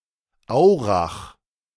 Aurach (German pronunciation: [ˈaʊ̯ʁax]
De-Aurach.ogg.mp3